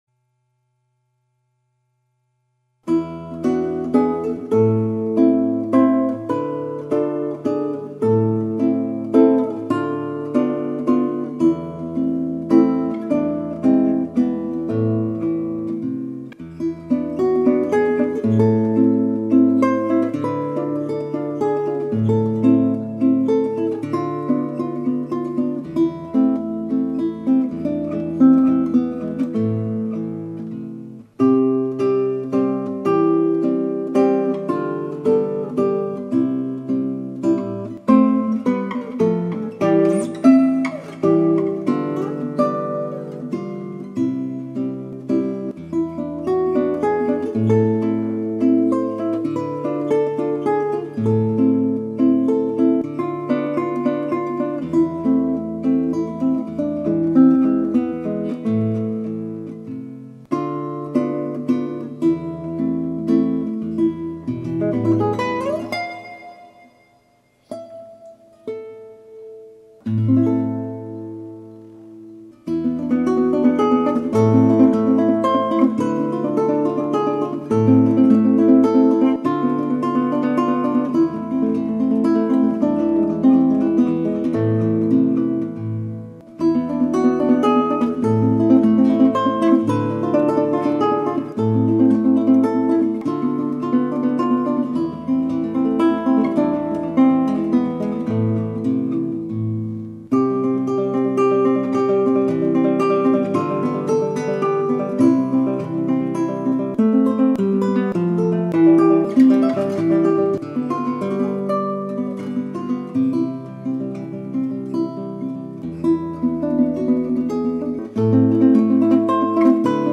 Bài gồm 3 đoạn ngắn, hai đoạn sau là hai biến tấu của ca khúc trình bày trong đoạn đầu. Biến tấu thứ hai là một đoạn tremolo rất du dương.